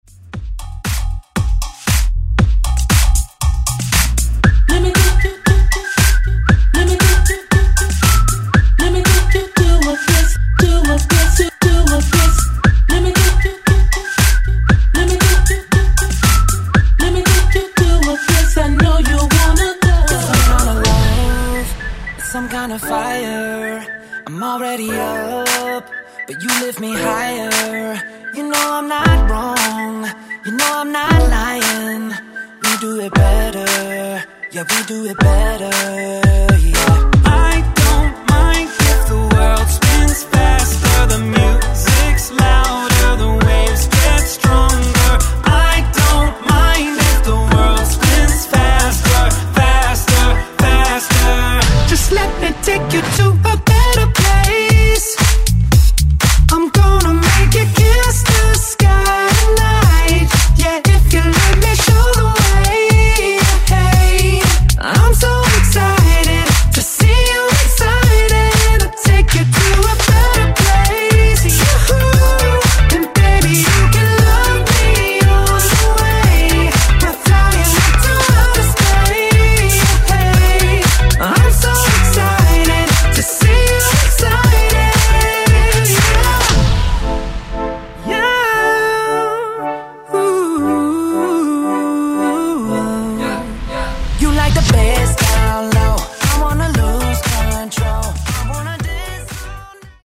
Genre: DANCE
Clean BPM: 125 Time